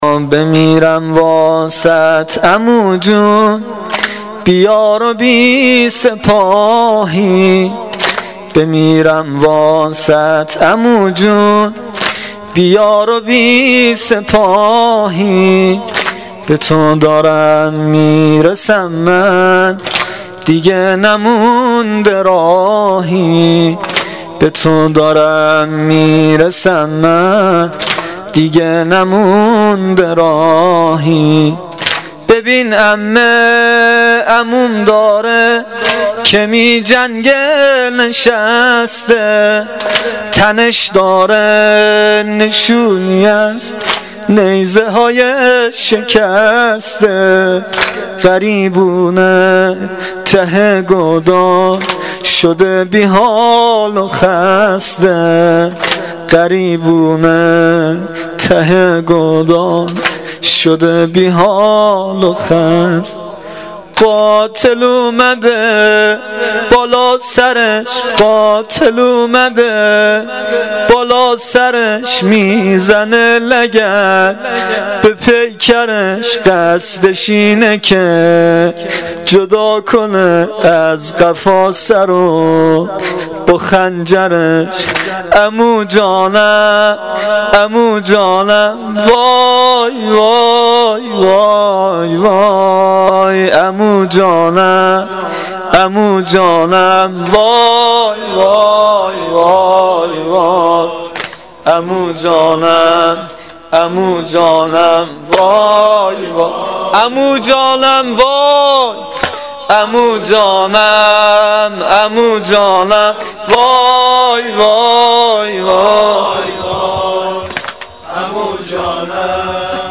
واحد سنگین شب پنجم محرم الحرام 1396
شب پنجم محرم